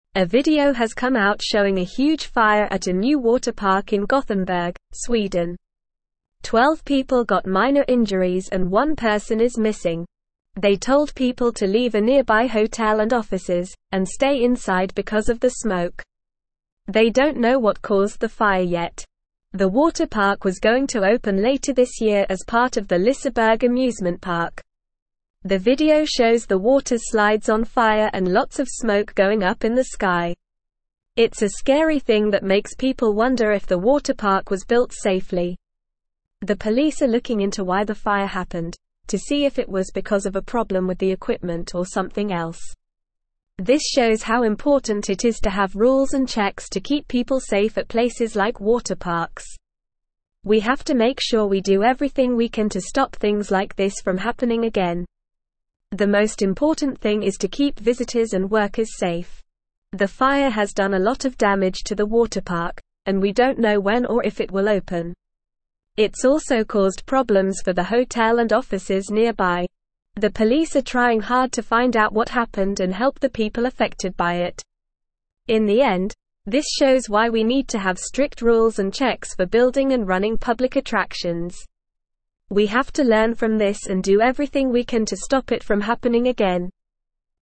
Normal
English-Newsroom-Upper-Intermediate-NORMAL-Reading-Massive-Fire-Engulfs-Newly-Built-Water-Park-in-Sweden.mp3